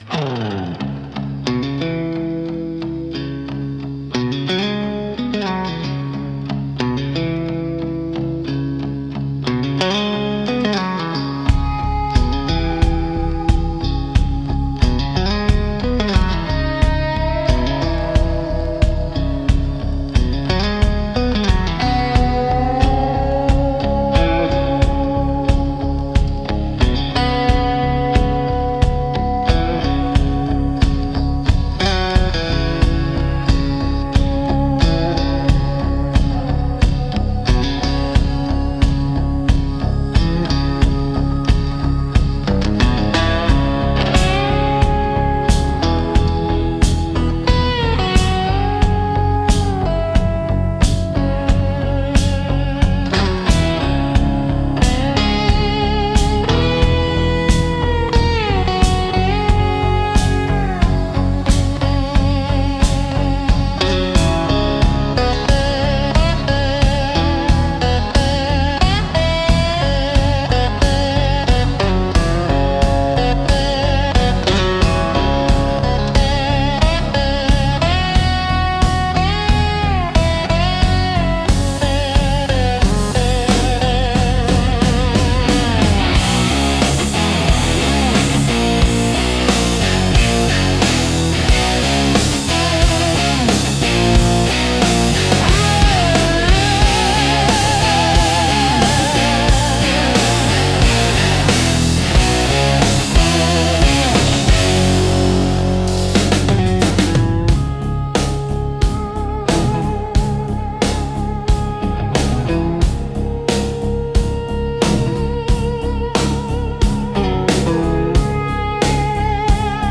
Instrumental - The Slow Unraveling 5.09